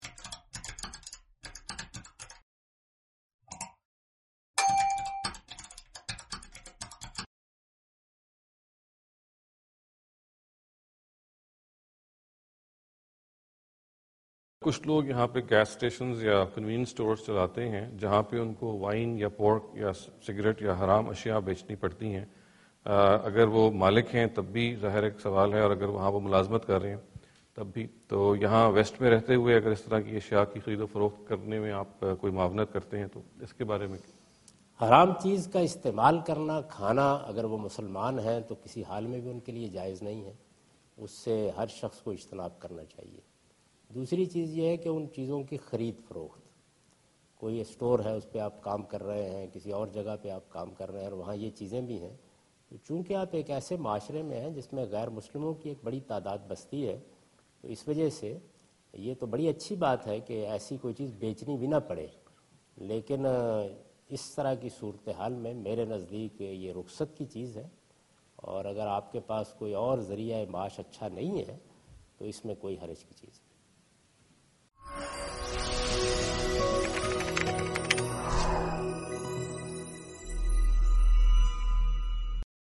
Javed Ahmad Ghamidi answer the question about "Selling Prohibited Products" during his visit to Georgetown (Washington, D.C. USA) May 2015.
جاوید احمد غامدی اپنے دورہ امریکہ کے دوران جارج ٹاون میں "حرام اشیاء فروخت کرنا" سے متعلق ایک سوال کا جواب دے رہے ہیں۔